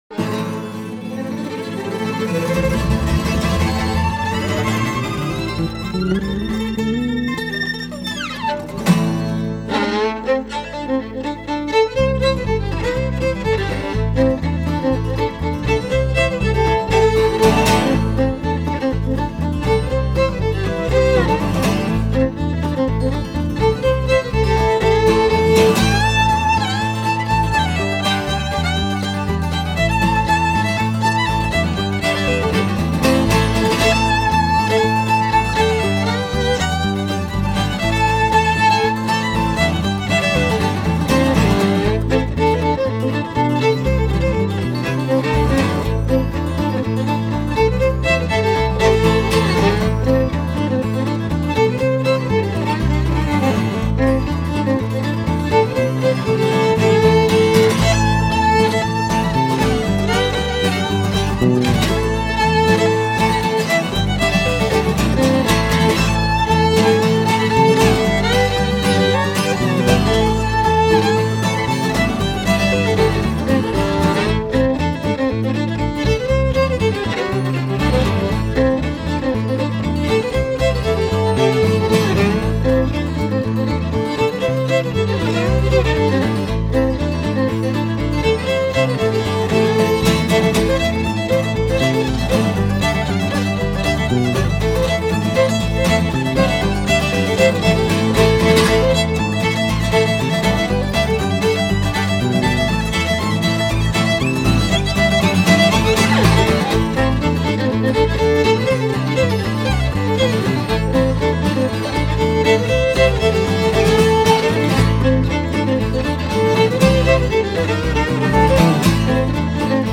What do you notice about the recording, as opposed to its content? recorded in Shutesbury Mass.